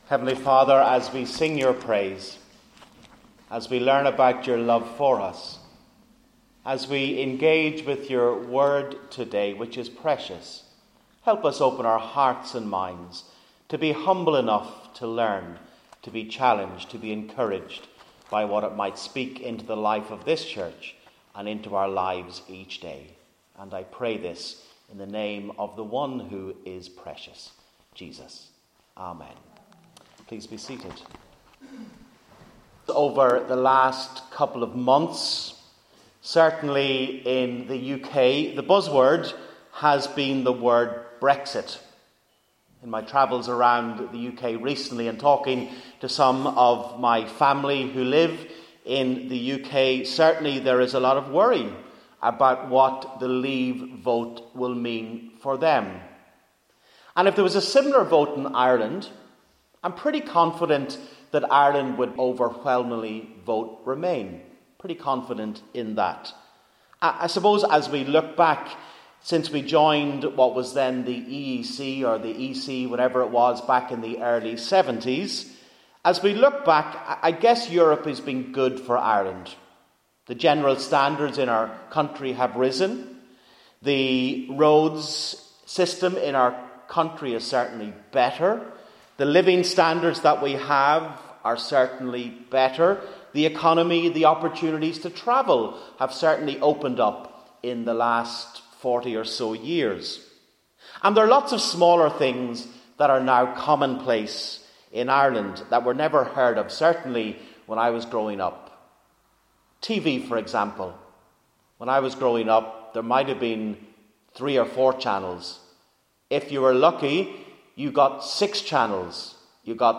Sermon Series: One Another Series - Virginia Group Church Of Ireland